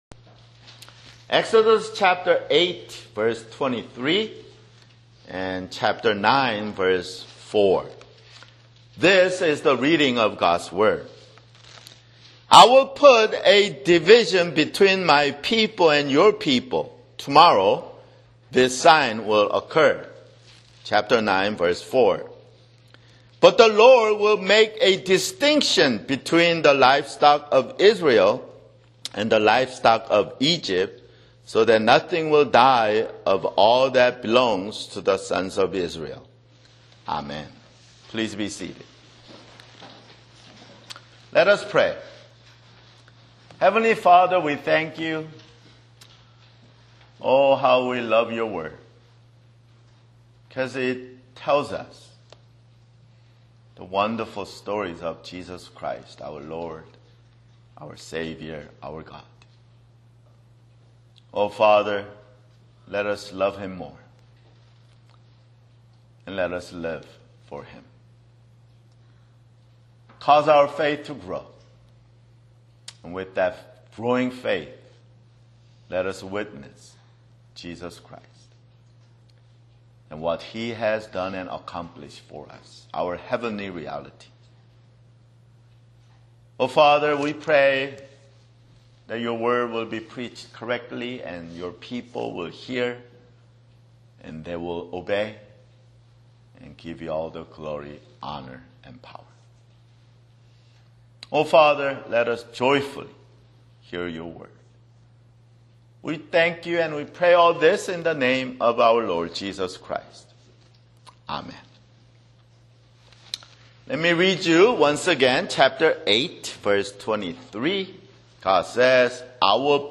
[Sermon] Exodus (22)